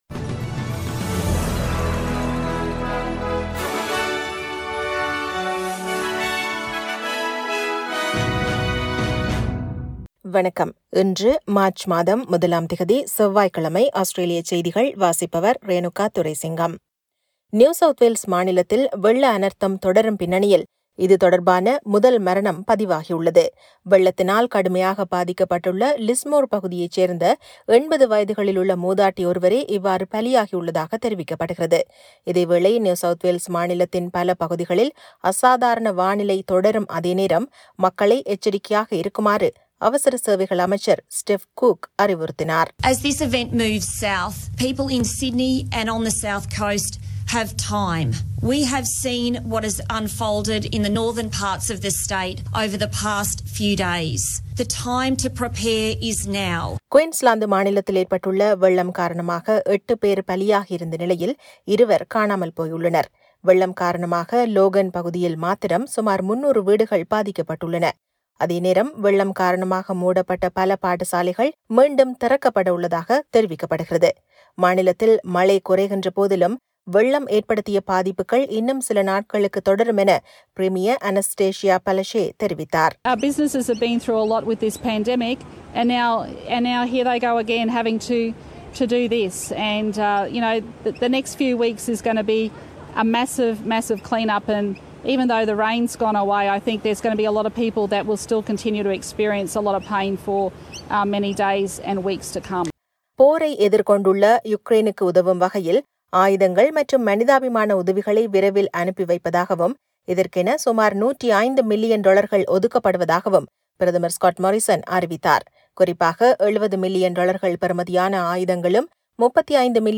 Australian news bulletin for Tuesday 01 March 2022.